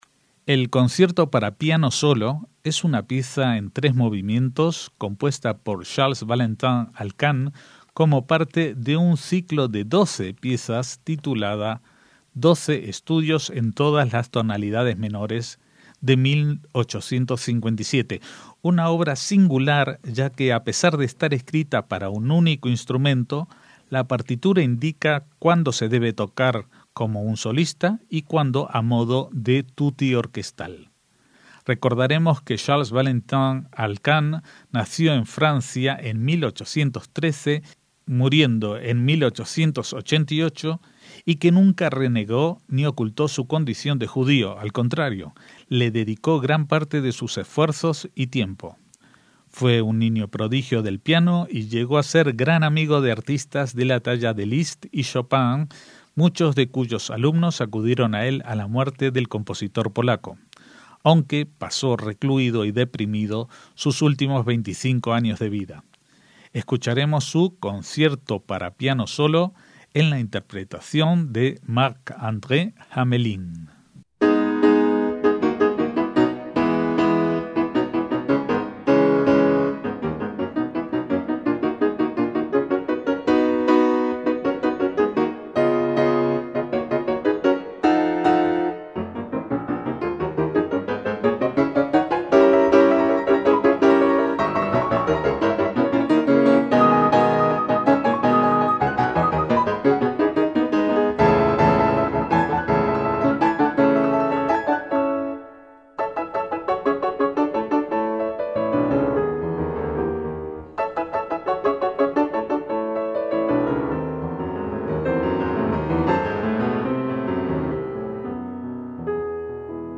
MÚSICA CLÁSICA
A los 25 años de edad había llegado a la cumbre de su carrera, cuando se retiró un tiempo para componer. Su obra, olvidada durante muchos años, ha sido reddescubierta por músicos como el pianista Marc-Andre Hamelin a quien escuchamos interpretando su Concierto para piano solo en tres movimientos, que forma parte de un ciclo de 12 estudios en tonalidades menores publicado en 1857. A pesar de su título, es una obra solista, pero en la que el intérprete debe tocar partes señaladas como tutti, solo y piano, como si tocara junto a una orquesta.